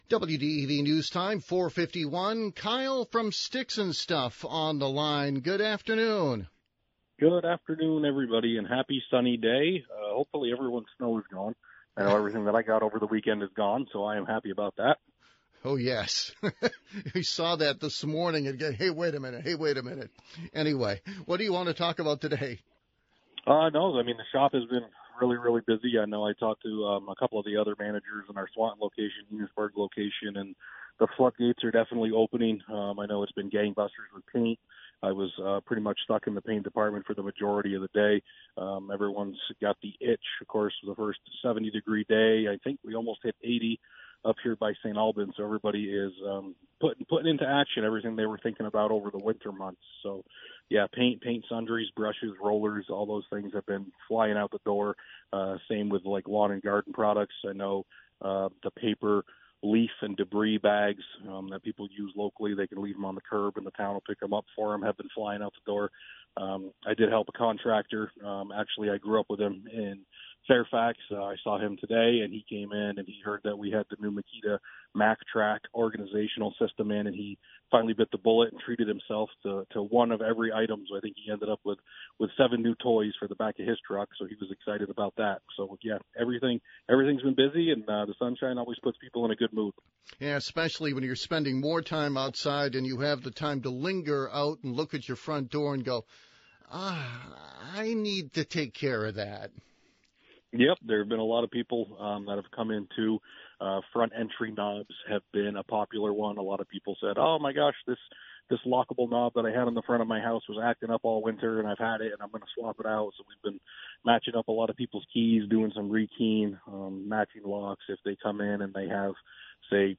live on WDEV and Radio Vermont Group.